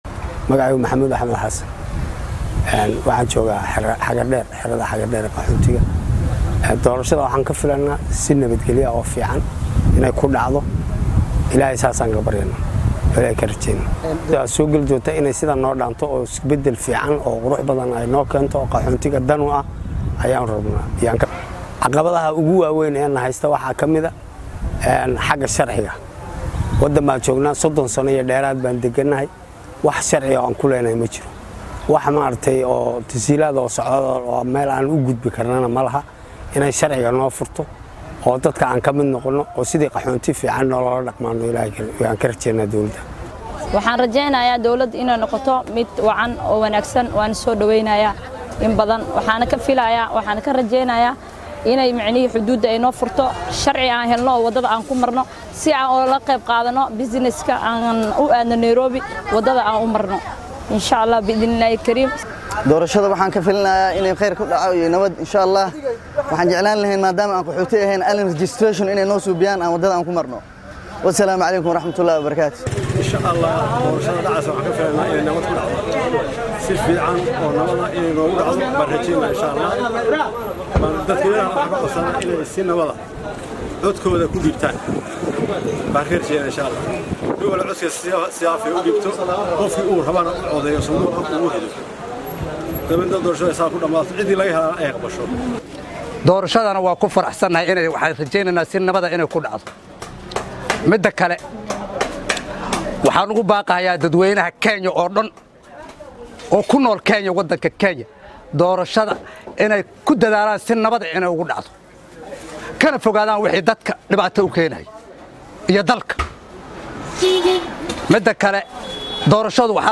Dadka qaxootiga ee ku nool xeryaha Dadaab ayaa muujiyay dareenkooda ku aaddan doorashada dalka waxayna ku baaqeen in shacabka ay si nabad ah ku codeyaan. Qaar ka mid ah qaxootiga Dadaab oo u warramay warbaahinta Star ayaa dareenkooda sidan u muujiyay.